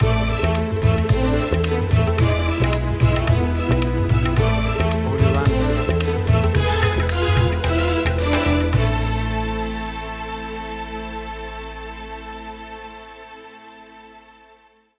Tempo (BPM): 110